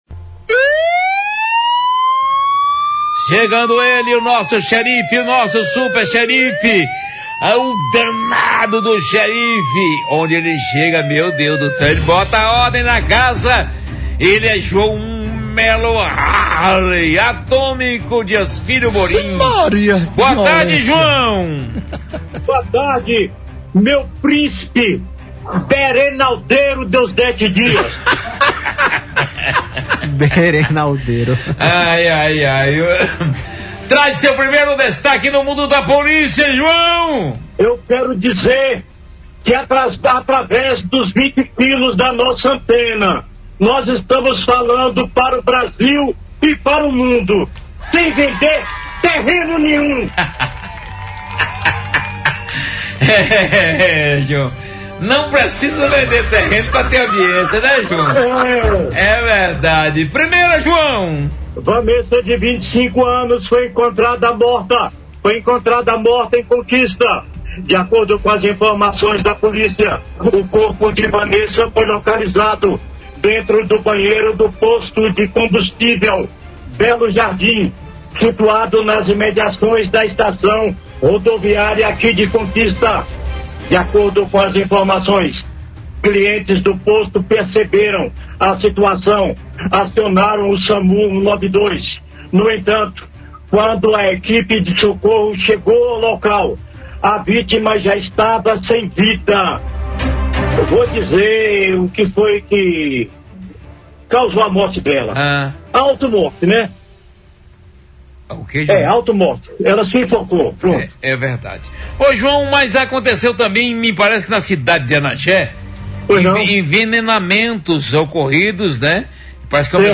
Reportagem Policial | homem morre atropelado, mulher perdeu a vida no banheiro em Vitória da Conquista